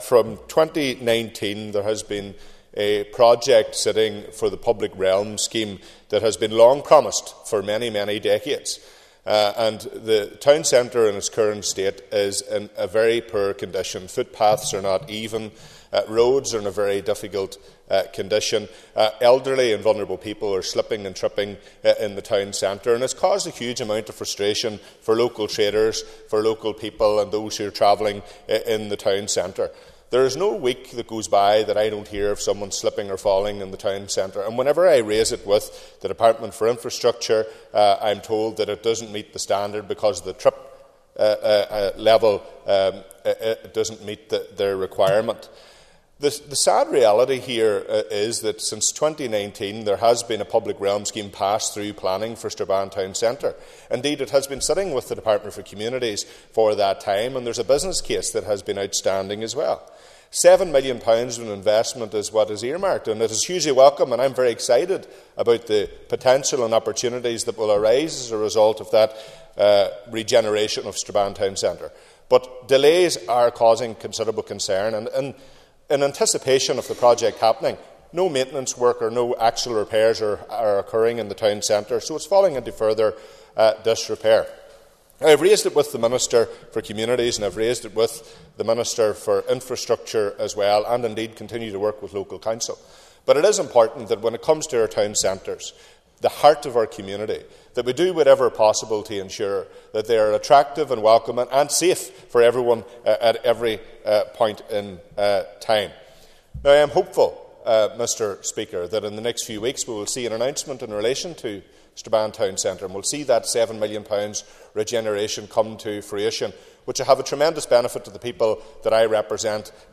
West Tyrone MLA Daniel McCrossan told the assembly the works were confirmed almost five years ago, but nothing has happened since then.